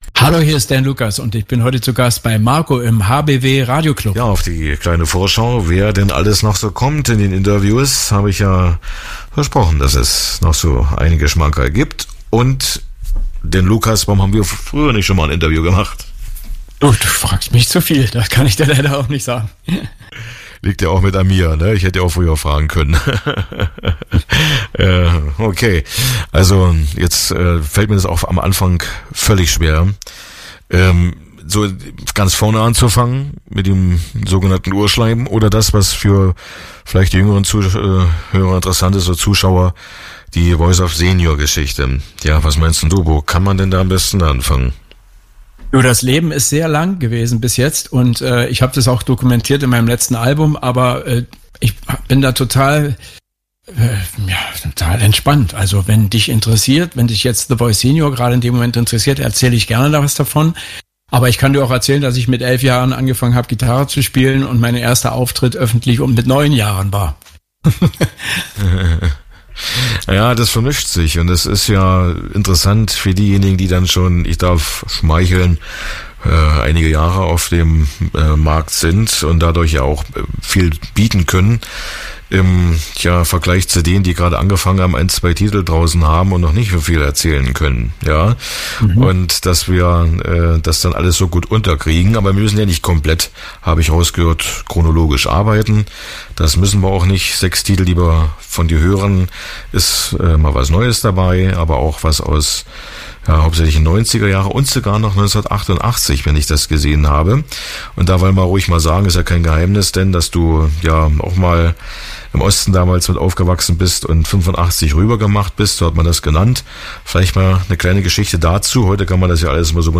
Dan Lucas zu Gast im Radioclub